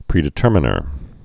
(prēdĭ-tûrmə-nər)